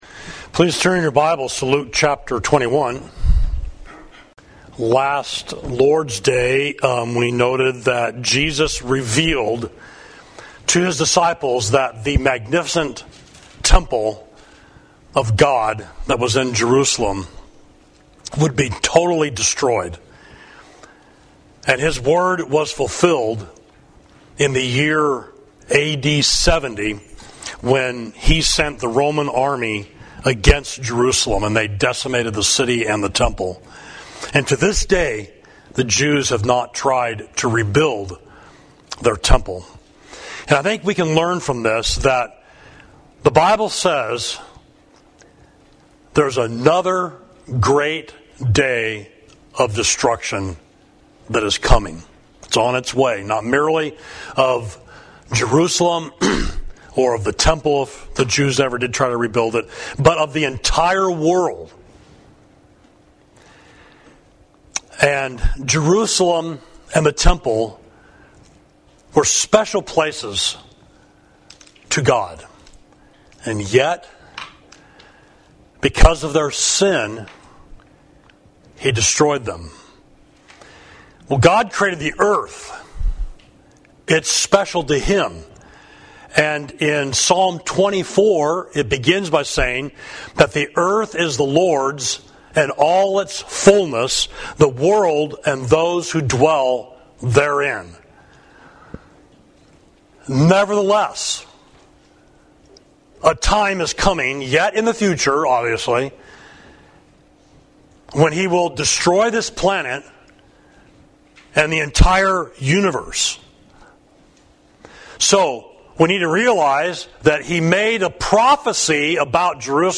Sermon: The Destruction of Jerusalem, (Part 3), Luke 21.25–38